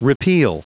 Prononciation du mot repeal en anglais (fichier audio)
Prononciation du mot : repeal